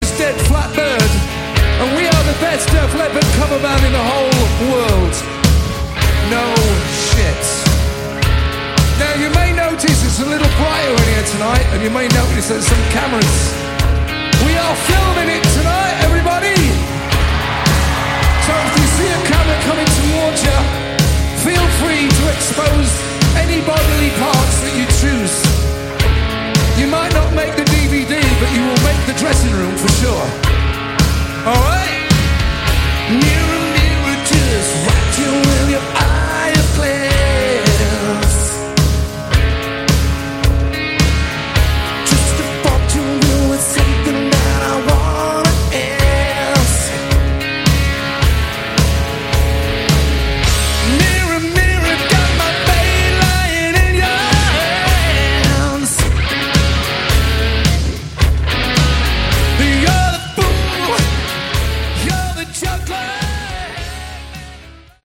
Category: Hard Rock
Recorded on their Las Vegas residency at 'The Joint' in 2013